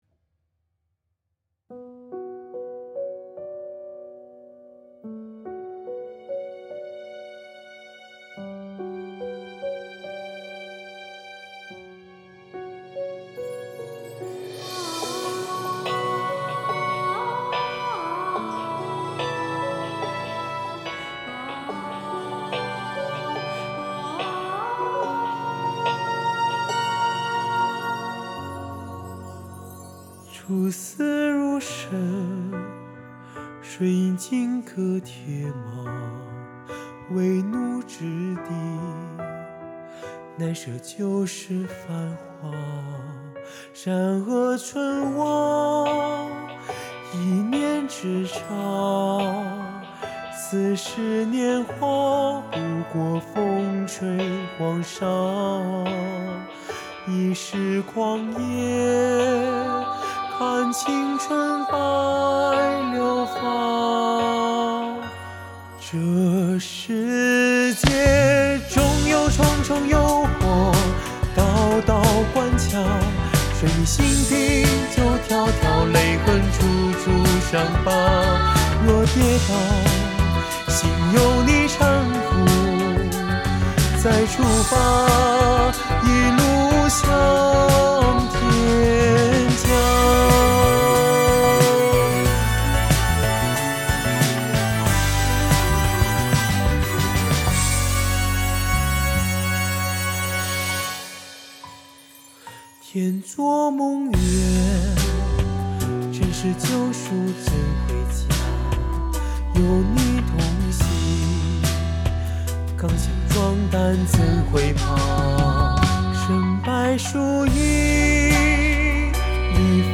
和声